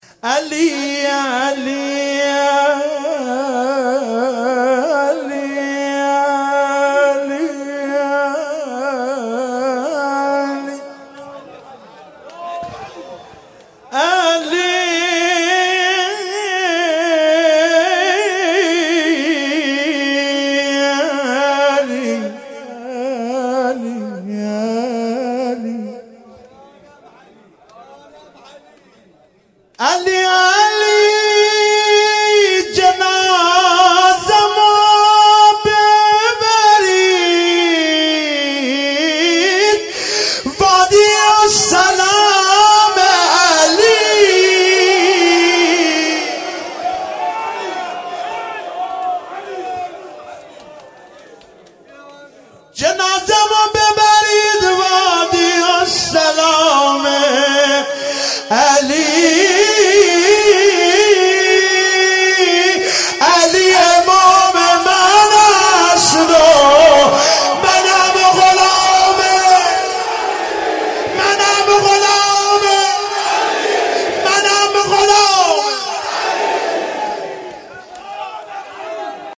شب چهارم محرم 95_شعر خوانی _مدح امیر المومنین علیه السلام